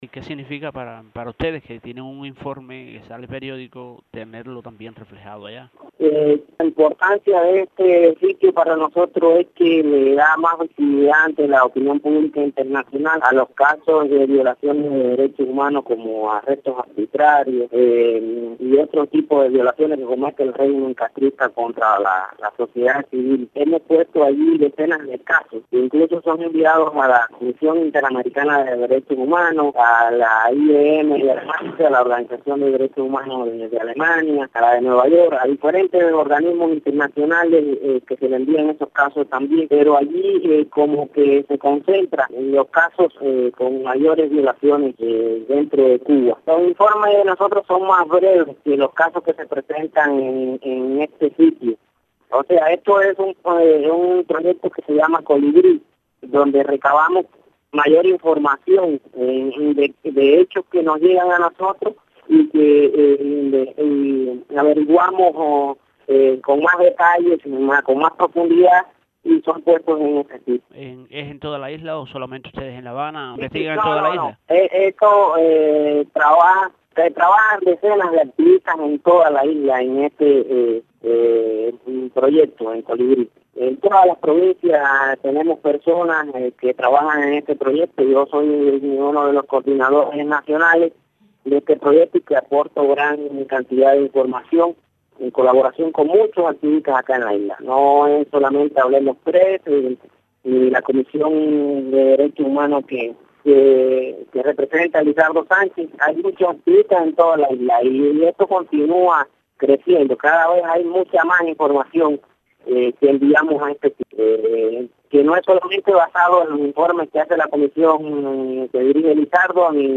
El periodista